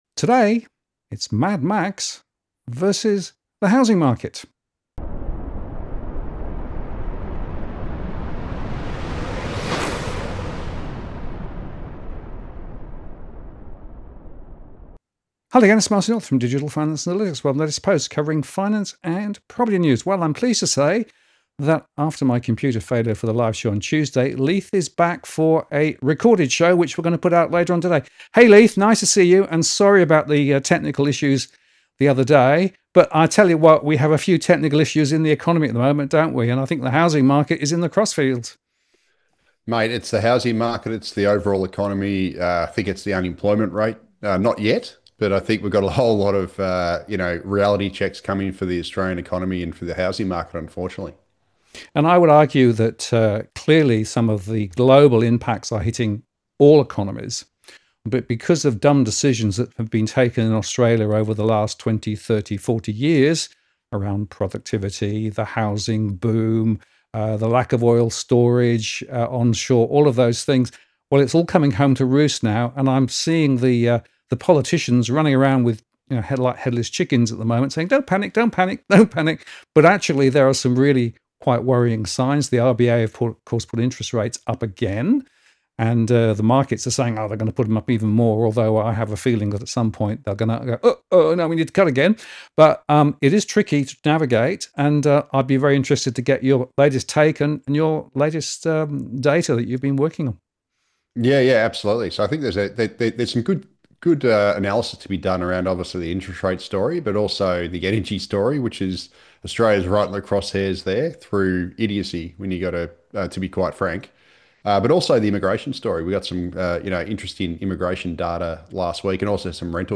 This recorded show replaced the previous advertised live show from Tuesday.